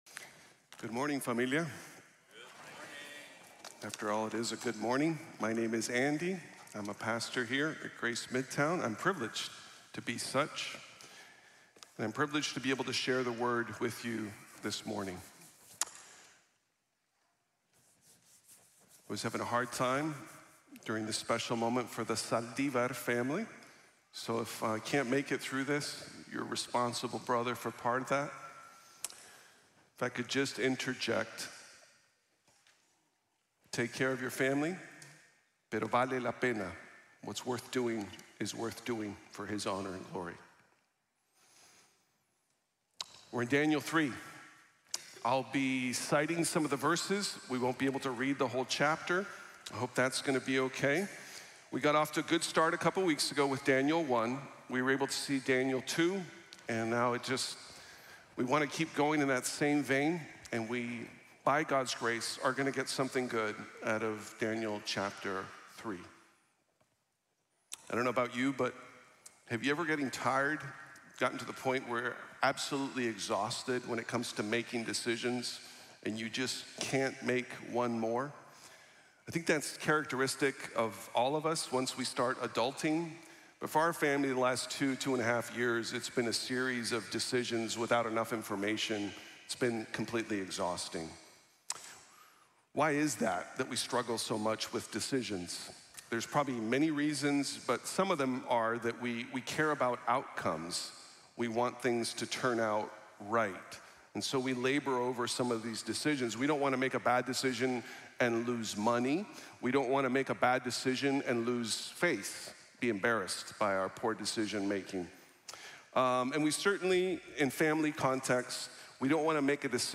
Servants of the Most High God | Sermon | Grace Bible Church